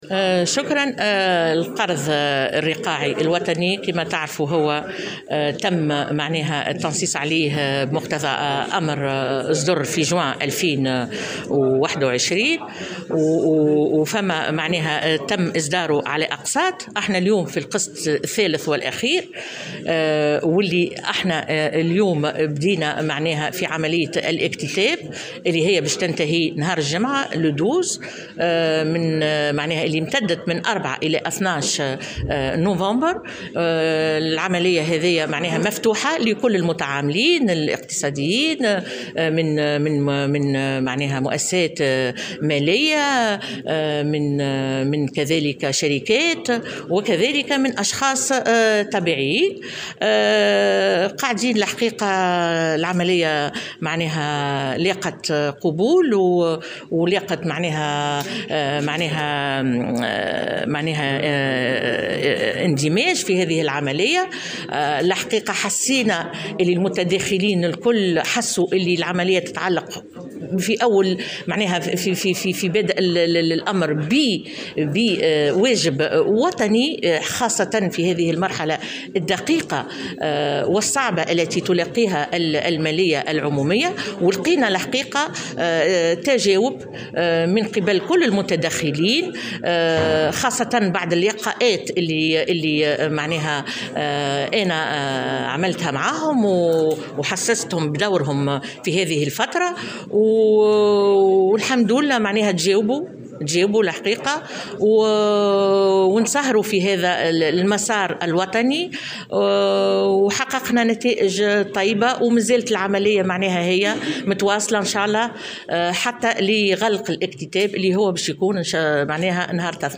قالت وزيرة المالية سهام البوغديري، في تصريح لمراسل الجوهرة أف أم، اليوم الأربعاء، إن قانون المالية التكميلي لسنة 2021 سيصدر قريبا في الرائد الرسمي للجمهورية التونسية، بعد أن تم النظر فيه خلال مجلس وزاري حيث وقع تحيين الفرضيات التي أُنجز على أساسها قانون المالية لسنة 2021.
وأكدت البوغديري، على هامش الملتقى الدولي للاحتفال باليوم العالمي للمحاسبة الذي ينظّمه مجمع المحاسبين، أن الإدارة انطلقت في العمل على إنجاز قانون المالية لسنة 2022 الذي سيحمل في طياته أحكاما جبائية جديدة بالإضافة إلى الباب المتعلق بالموازنة، نافية في هذا الإطار صحة ما تم تداوله عبر مواقع التواصل الاجتماعي وبعض وسائل الإعلام من تفاصيل بخصوص مشروع هذا القانون.